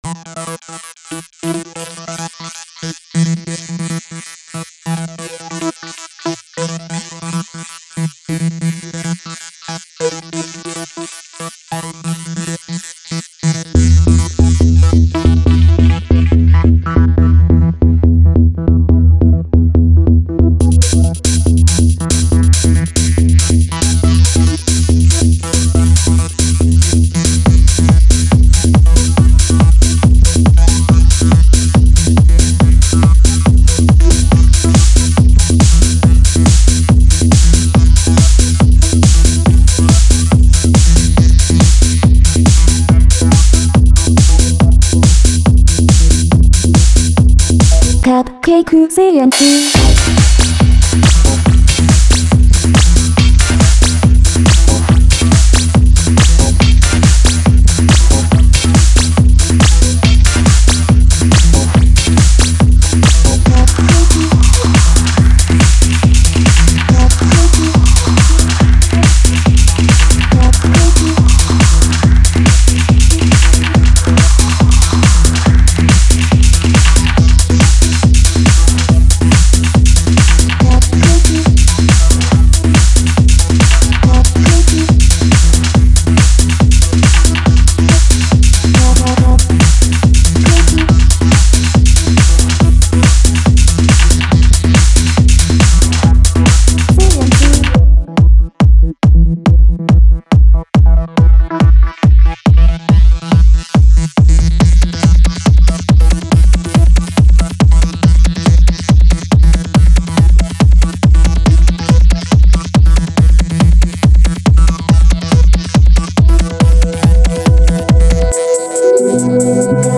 動画中で使っているBGMはこちら。
cupcakecnc_bgm01.mp3